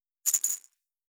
３７６塩コショウ,サラサラ,パラパラ,ジャラジャラ,サッサッ,ザッザッ,シャッシャッ,シュッ,パッ,
効果音厨房/台所/レストラン/kitchen